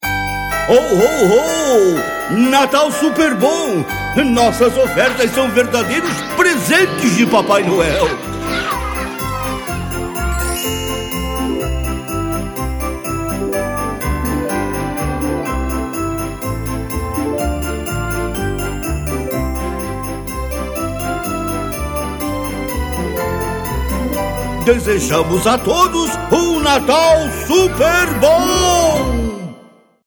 Sua voz é uma das mais versáteis da dublagem brasileira, pois ele possui quatro ou mais tipos de vozes diferentes, desde a fanhosa até seu famoso vozeirão profundo.